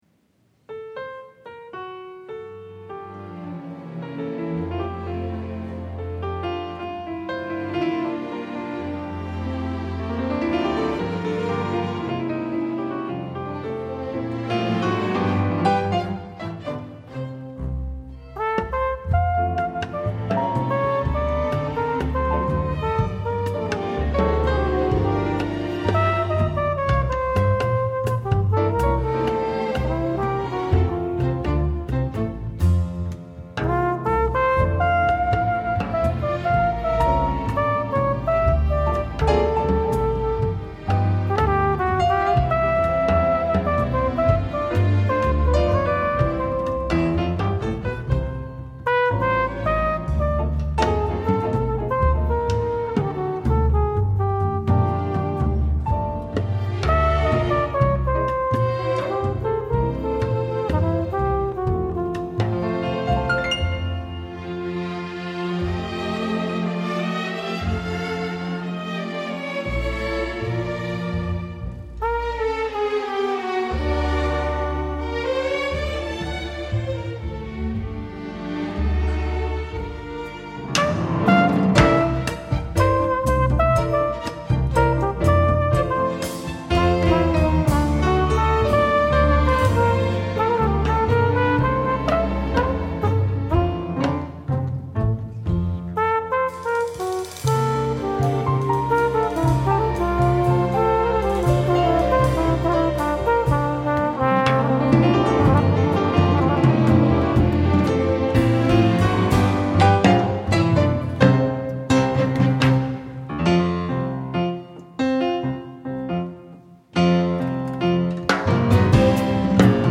-   爵士及藍調 (295)
★ 洋溢濃厚拉丁風情，充滿華麗生命力的明亮旋律！
★ 優異錄音效果，展現大珠小珠落玉盤的極致琴聲！